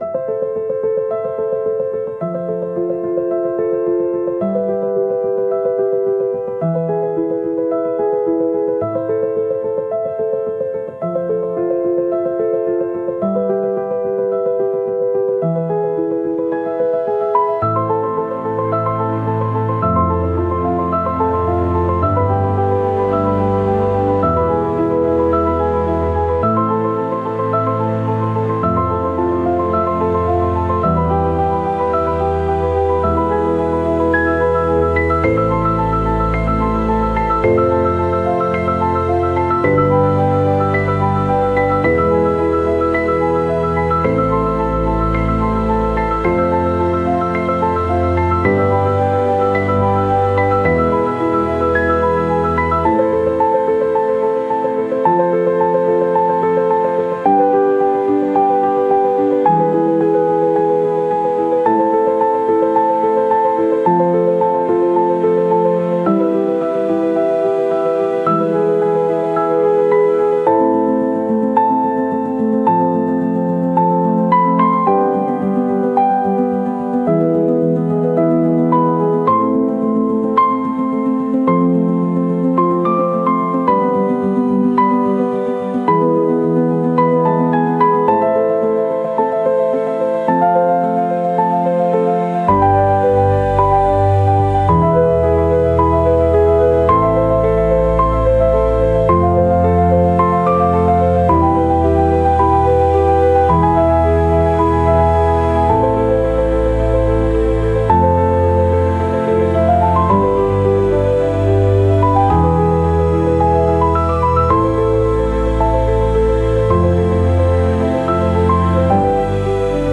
Classical, Cinematic
Dramatic, Hopeful, Sentimental, Sad, Elegant
108 BPM